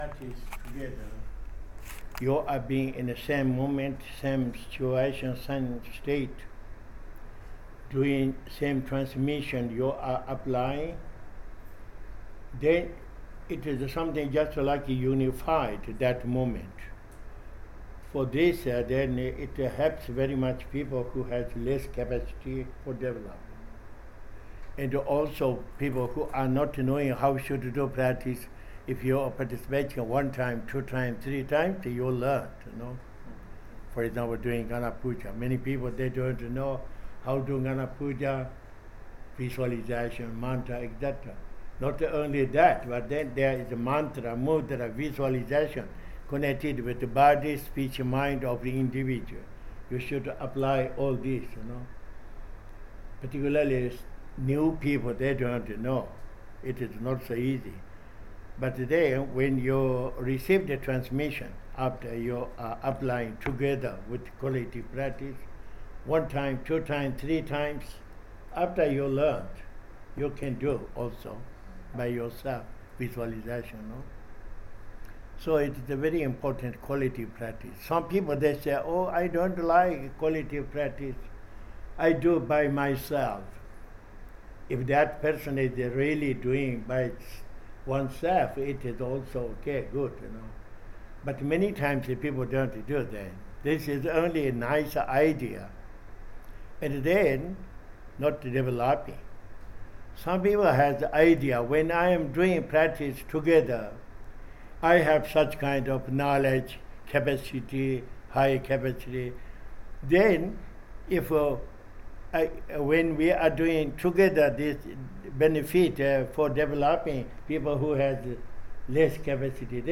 Un insegnamento informale
dopo la Trasmissione Mondiale del Guruyoga
Santiago, Cile, 17 novembre 2014